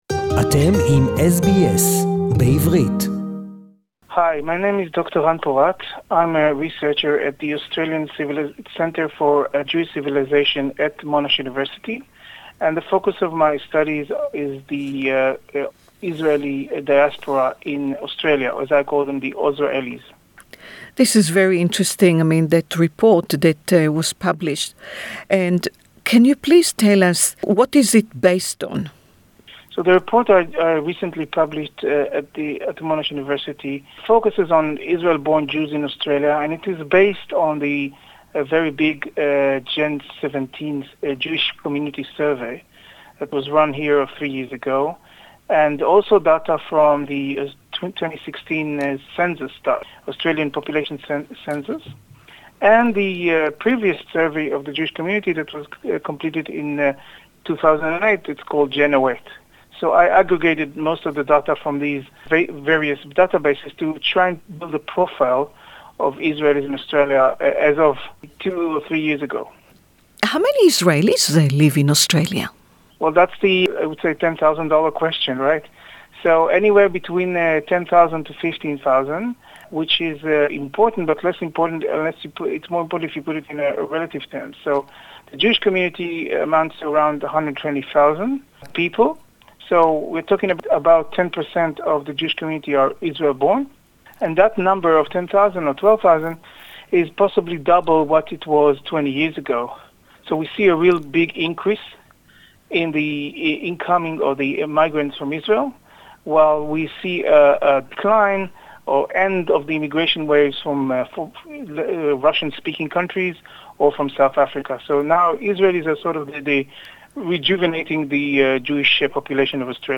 English interview) The Monash University report highlights important facts about the Australian Israeli community, such as demographics, education, language, their difficulties they are facing as migrants, their connection with the local Jewish community and much more..